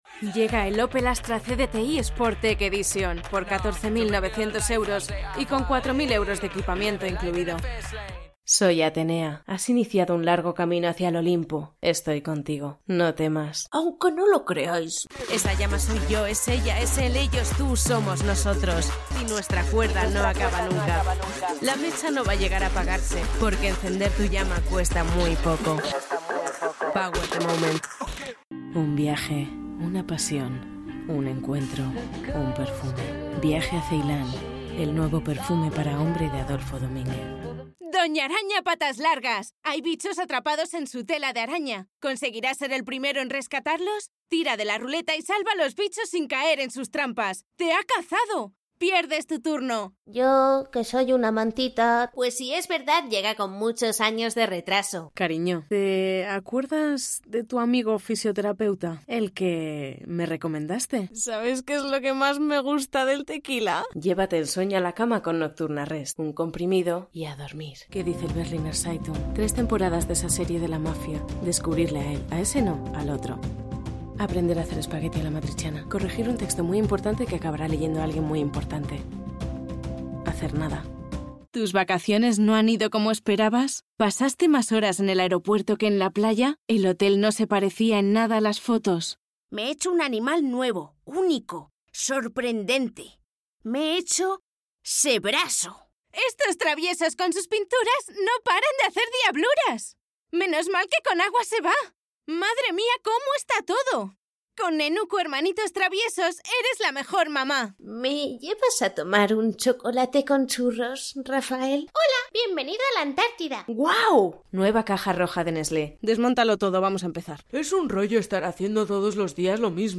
Voz natural, profunda o ligera, vesátil, con capacidad de crear diferentes registros.
kastilisch
Sprechprobe: Sonstiges (Muttersprache):
Natural voice, deep or gentle, versatile, able to create different vocal ranges.